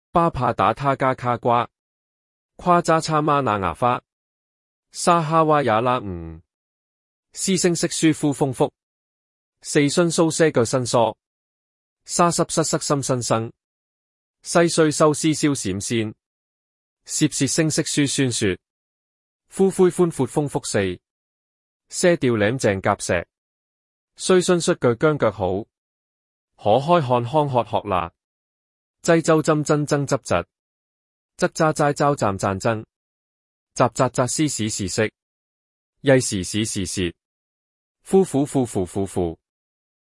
116字学会粤拼-ttsmaker-家辉.mp3